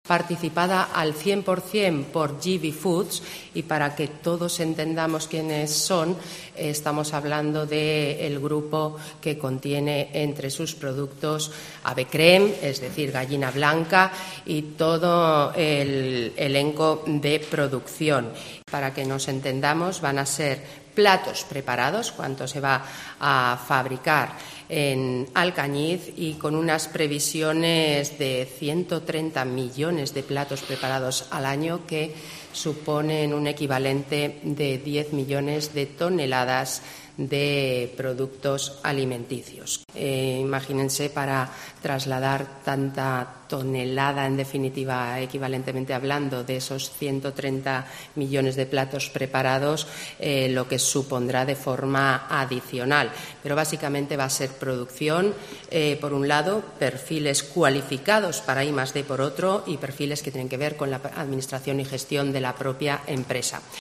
La consejera de Economía, Marta Gastón, habla del proyecto de GB Foods en Alcañiz.